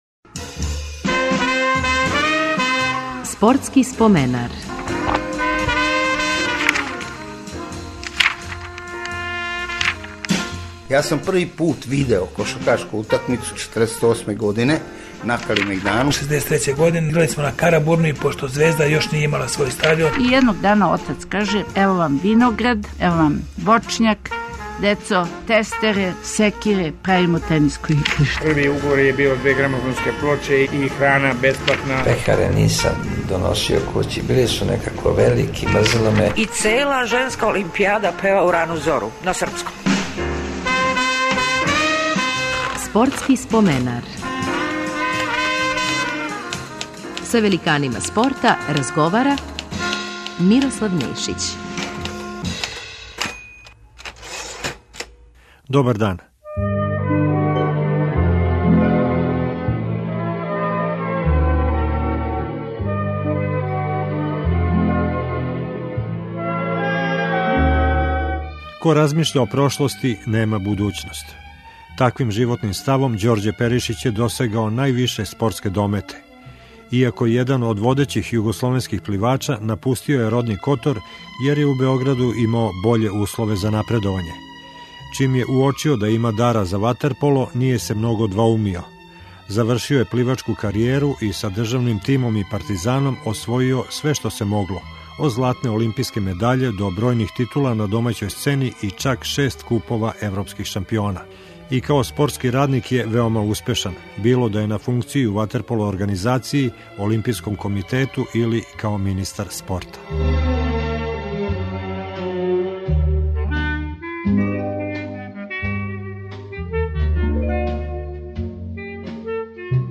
Репризираћемо разговор с пливачем и ватерполистом Ђорђем Перишићем.